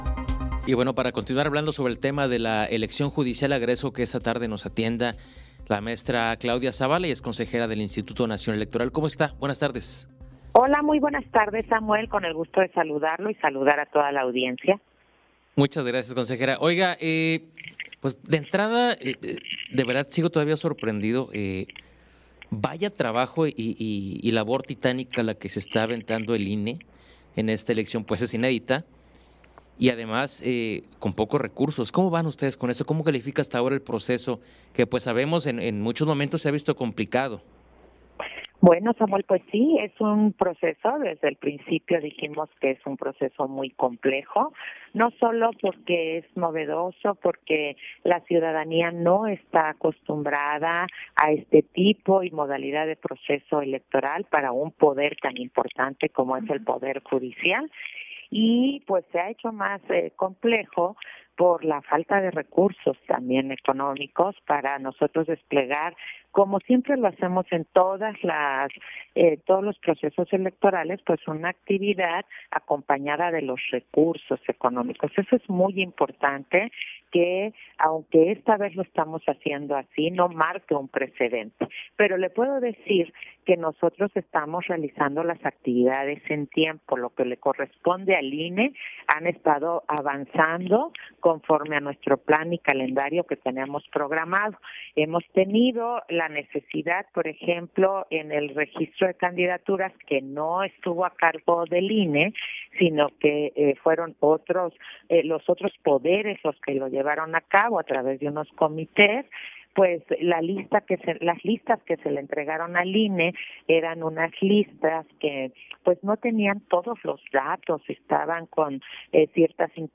Entrevista
Audio de la entrevista